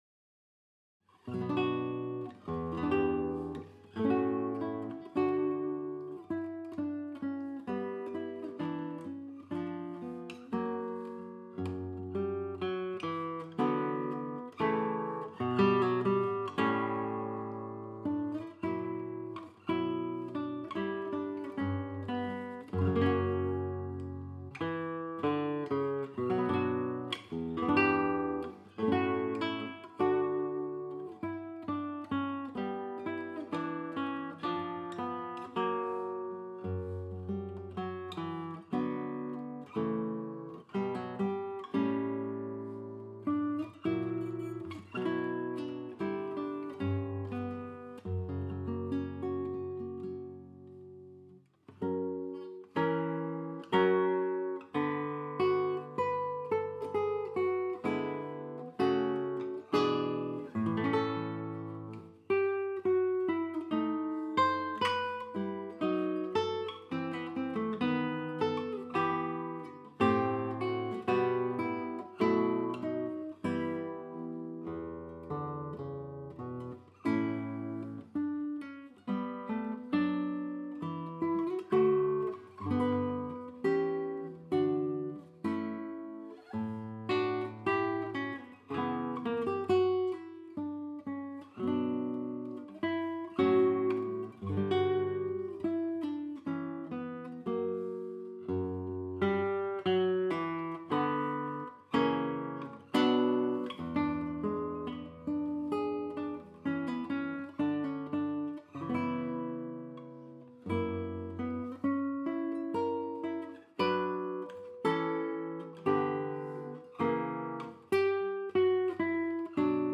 KU279 Walnut and Cedar classical guitar
Wonderful warm clear tone.